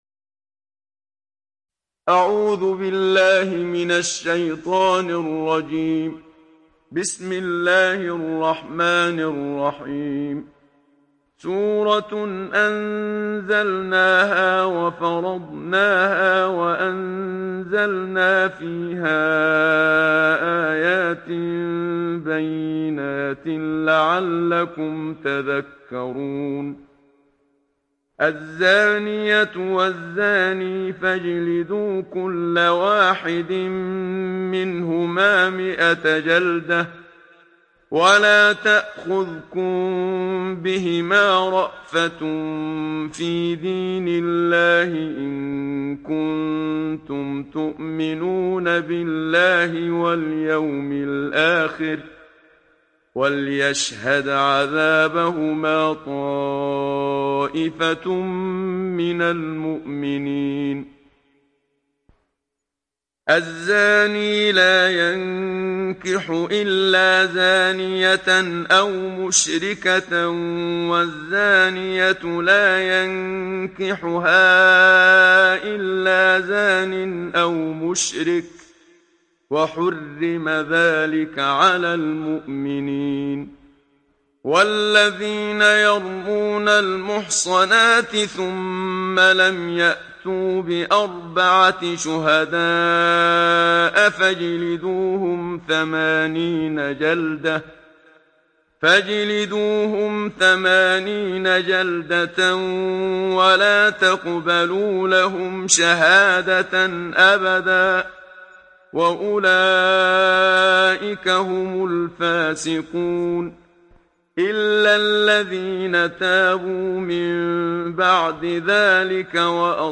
تحميل سورة النور mp3 بصوت محمد صديق المنشاوي برواية حفص عن عاصم, تحميل استماع القرآن الكريم على الجوال mp3 كاملا بروابط مباشرة وسريعة